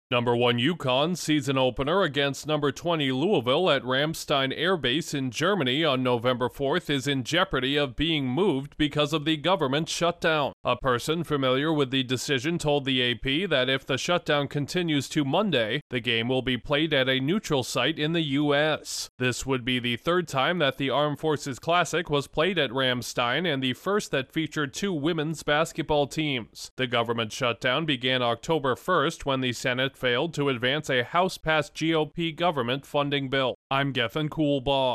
The ongoing government shutdown could lead to a venue change for a high-profile college basketball game. Correspondent